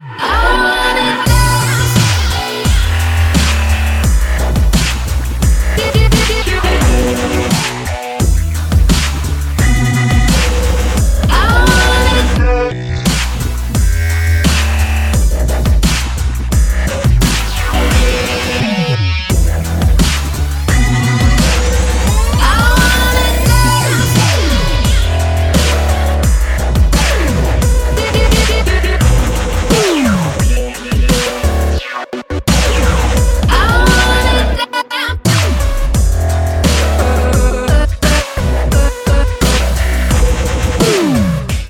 • Качество: 128, Stereo
громкие
женский вокал
dance
Electronic
качающие
Bass
Dubstep
клубные